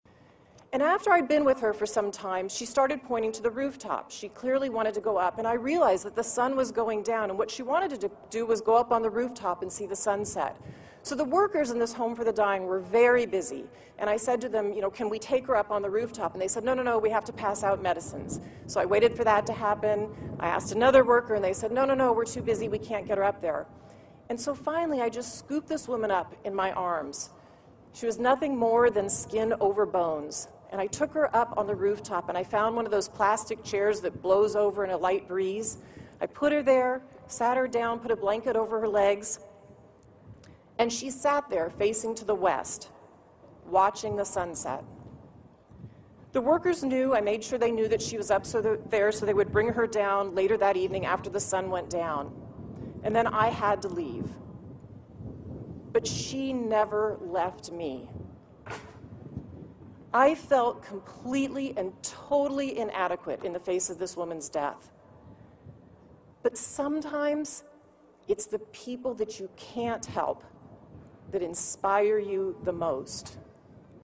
公众人物毕业演讲第32期:比尔盖茨夫妇于斯坦福大学(13) 听力文件下载—在线英语听力室